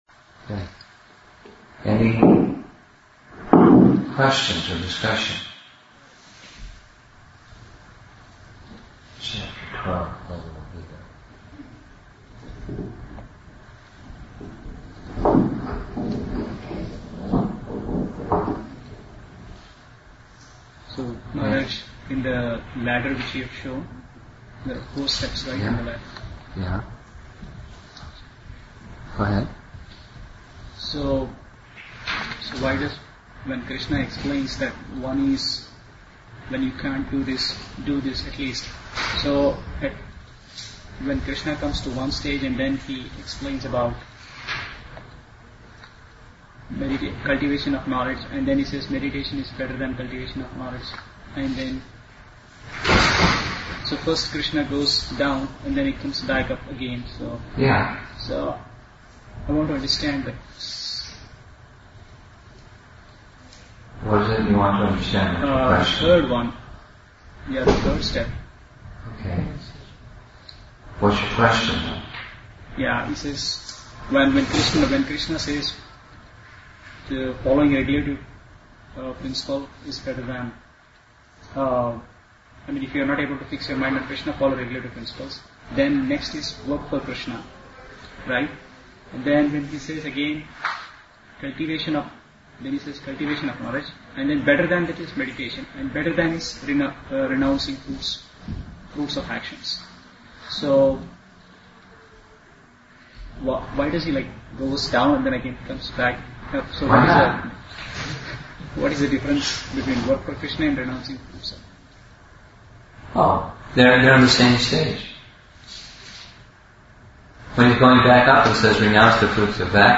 Question and Answers on BG Chapter 12 – Devotional Service